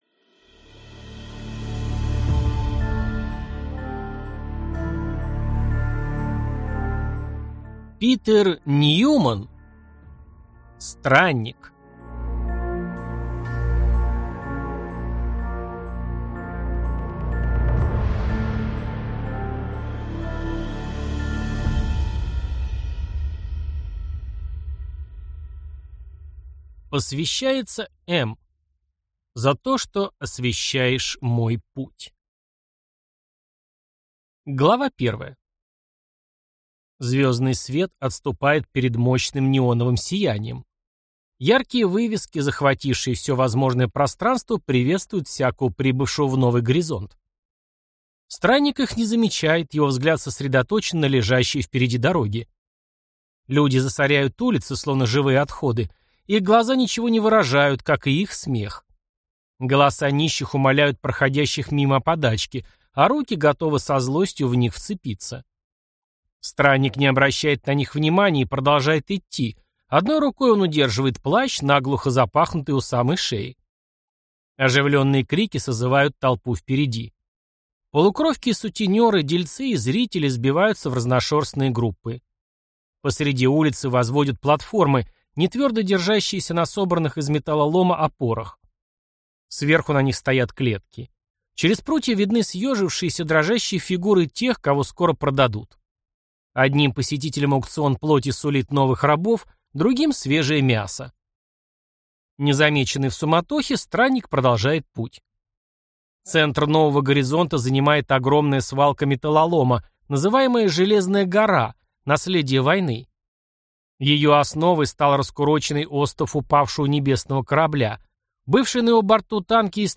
Аудиокнига Странник | Библиотека аудиокниг